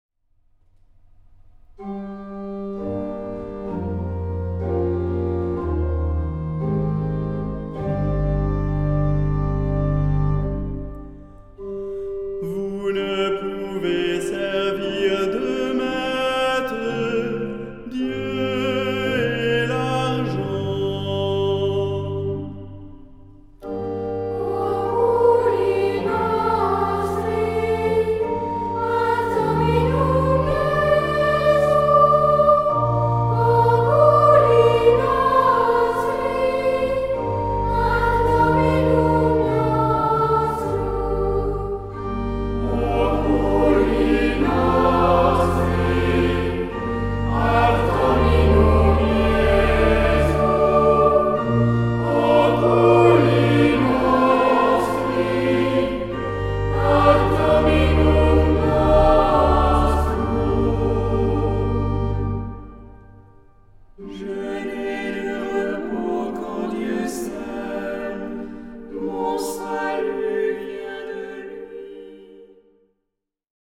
Genre-Style-Form: troparium ; Psalmody
Mood of the piece: collected
Type of Choir: SAH OR SATB  (4 mixed voices )
Instruments: Organ (1) ; Melody instrument (optional)
Tonality: G minor